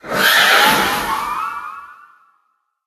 Cri de Sylveroy, le Cavalier du Froid dans Pokémon HOME.
Cri_0898_Cavalier_du_Froid_HOME.ogg